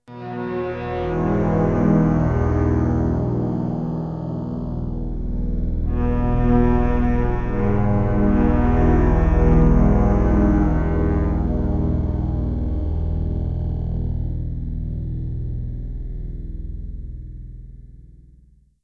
orchestral.ogg